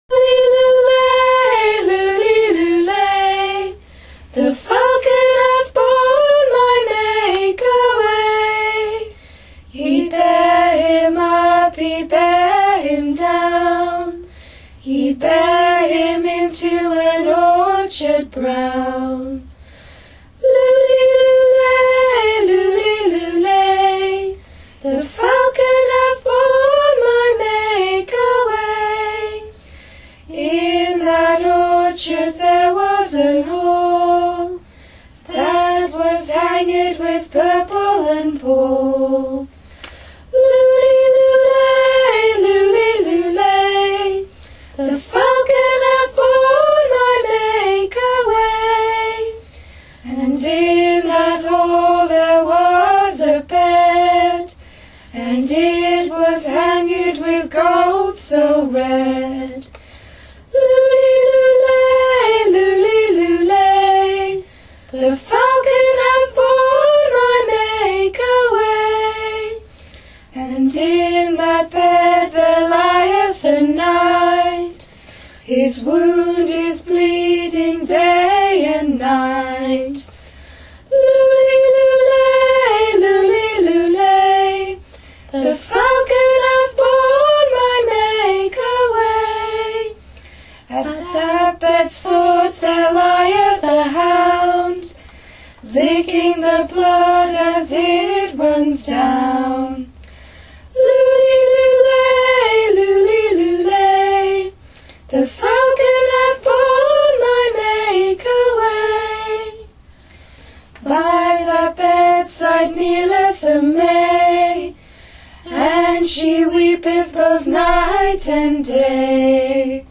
We have also provided two alternative sung versions of the poem for you to listen to:
The Corpus Christi Carol (sung by three first-year students
But traditionally it would have been repeated at the end of each stanza, as it is in the sung version of the ballad.